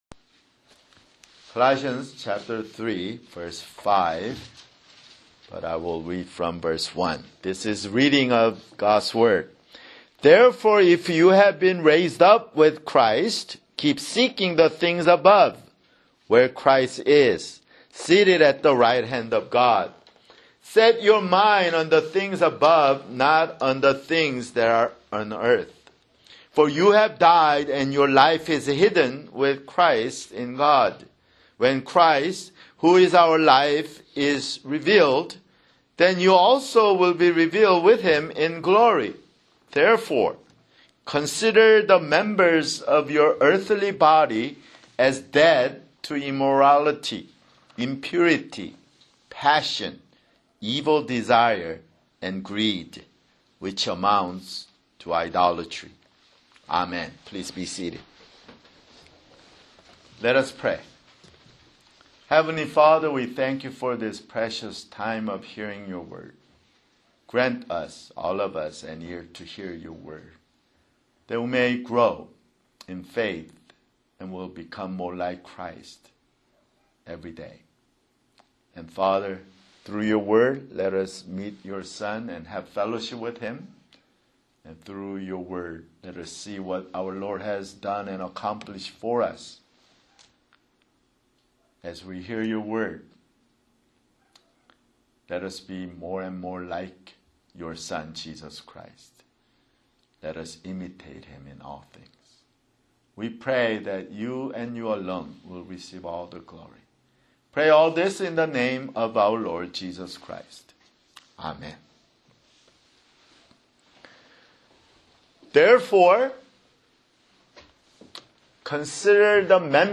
[Sermon] Colossians (57)